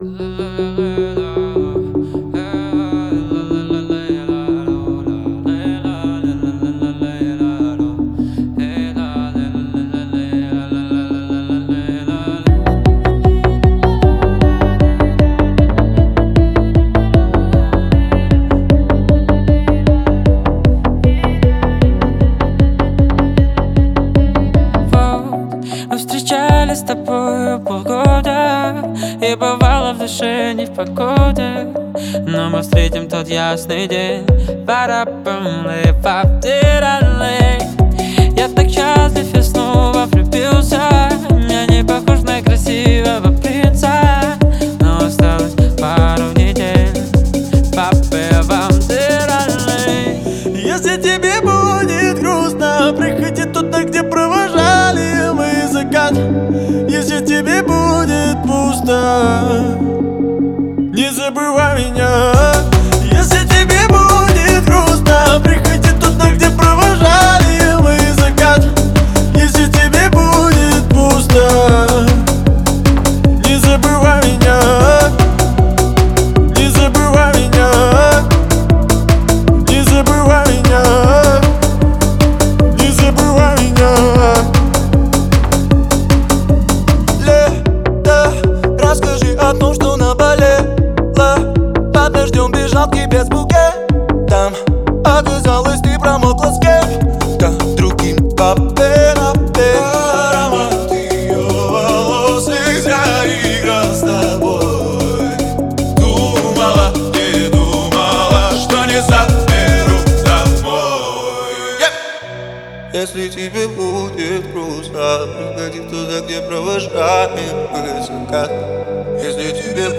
это эмоциональная композиция в жанре поп с элементами R&B